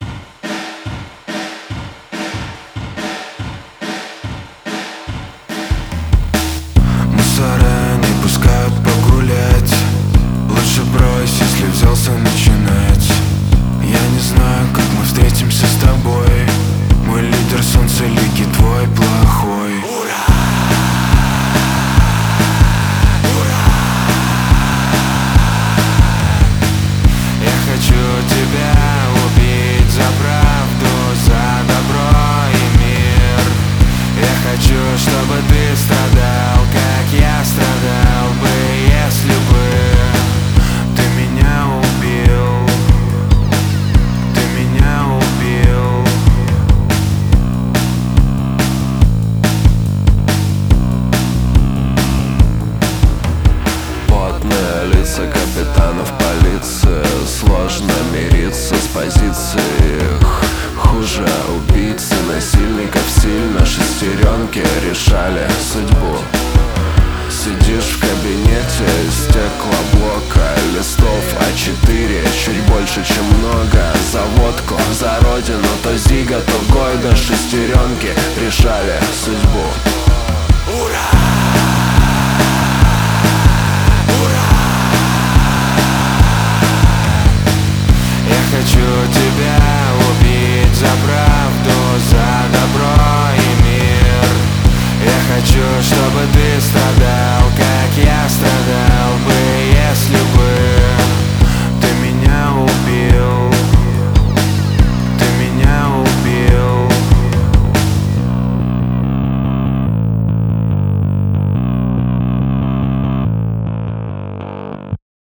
• Жанр: Панк-рок, Русская музыка